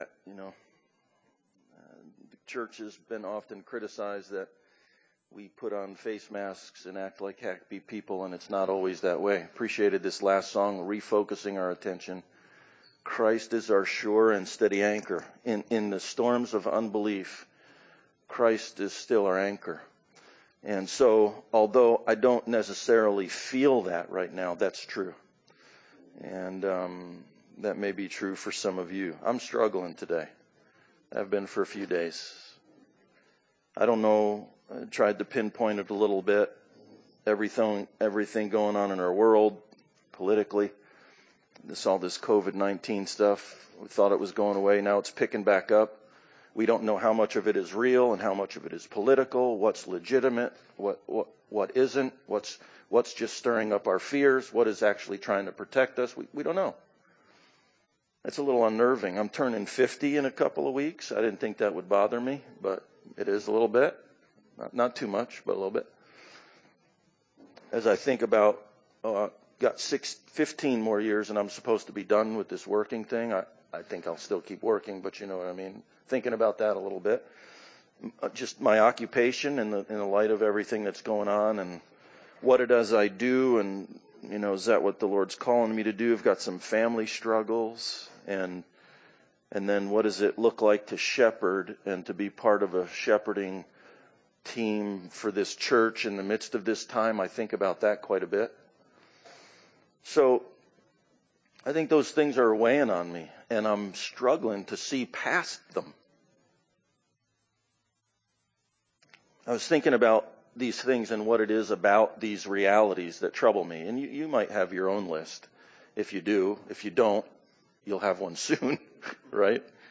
Passage: Hebrews 12:1-11 Service Type: Sunday Service Bible Text